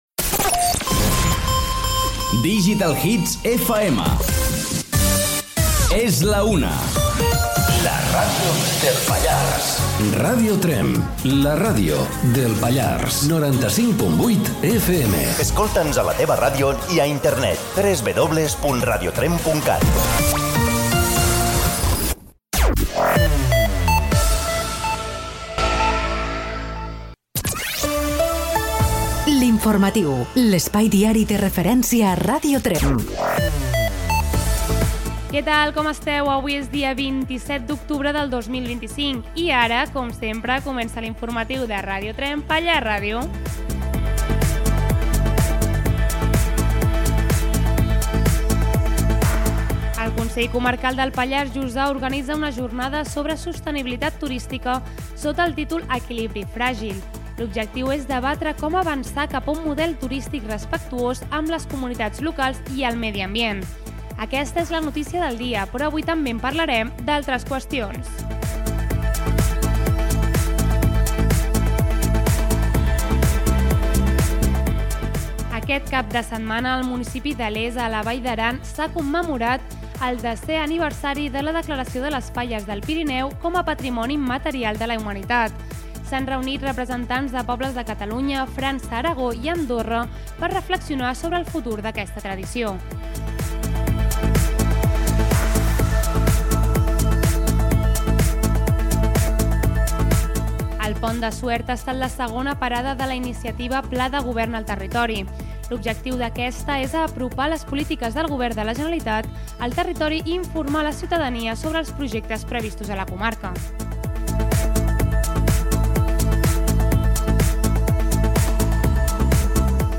Indicatiu de Digital Hits, hora, indicatiu de Ràdio Tremp, careta, data, sumari informatiu, jornada sobre el turisme al Pallars feta a la Poble de Segur, les falles del Pirineu, Pont de Suert
Informatiu